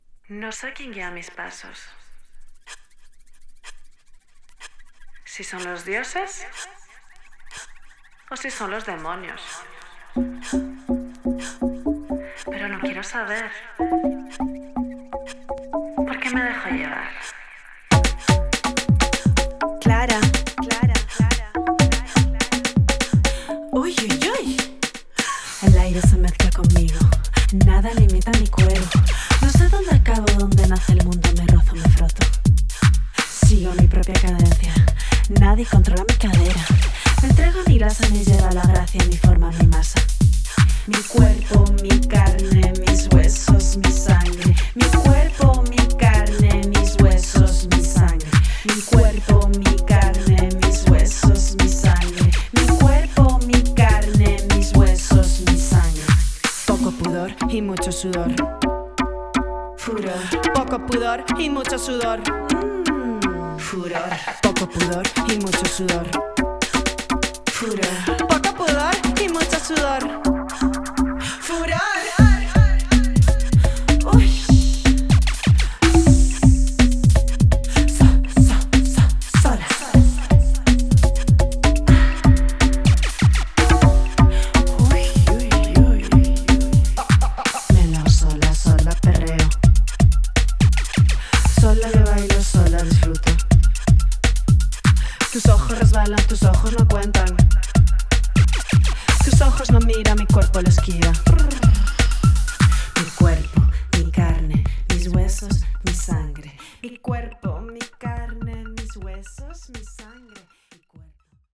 スペイン語で「体、肉、骨、血」と繰り返されるイントロから撃ち抜かれる、肉感的なキラー・ベースチューン!